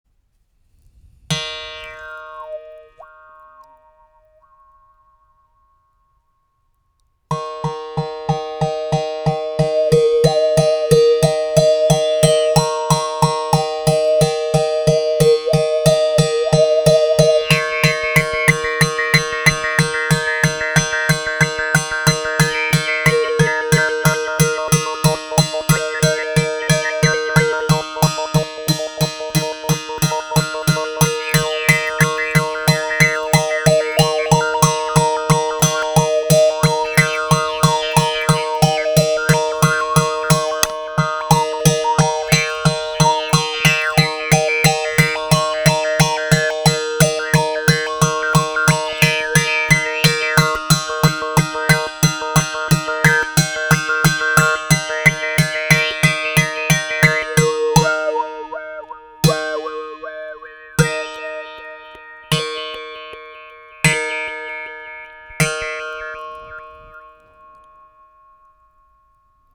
ARC EN BOUCHE 1 CORDE
Les sons proposés ici sont réalisés sans effet.
La baguette sera utilisée pour percuter la corde.